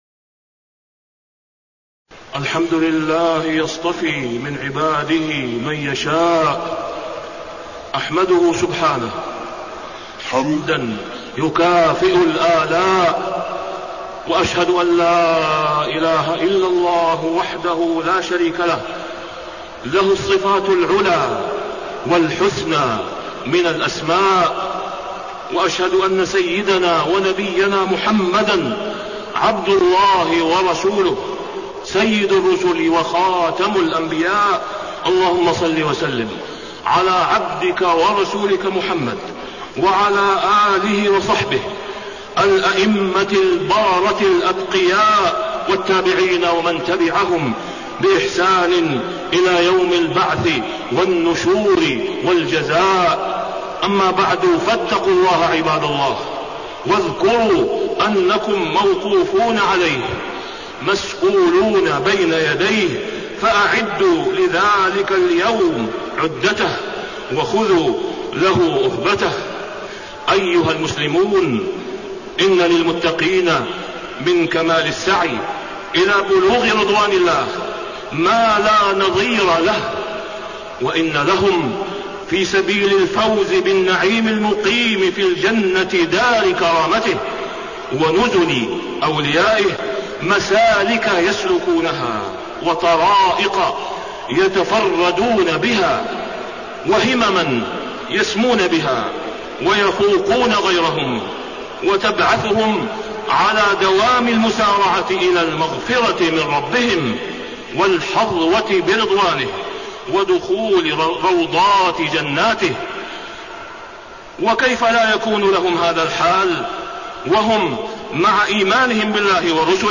تاريخ النشر ٥ صفر ١٤٣٣ هـ المكان: المسجد الحرام الشيخ: فضيلة الشيخ د. أسامة بن عبدالله خياط فضيلة الشيخ د. أسامة بن عبدالله خياط حال عباد الله المتقين